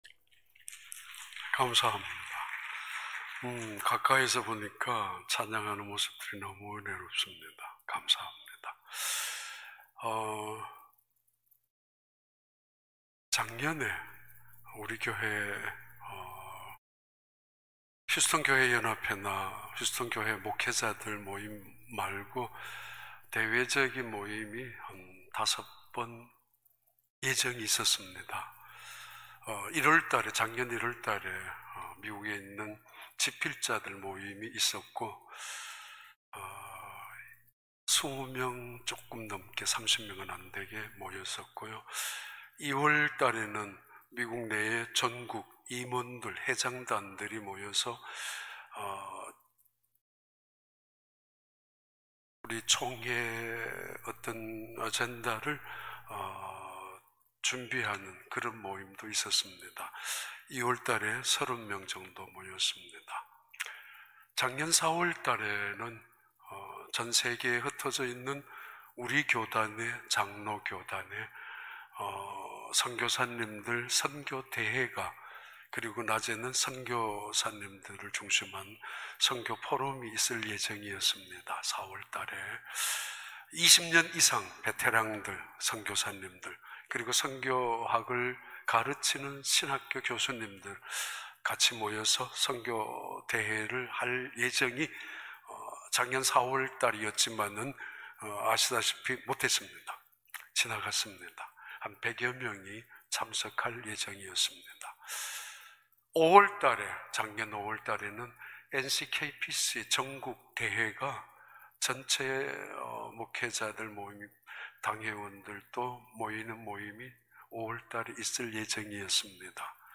2021년 1월 31일 주일 4부 예배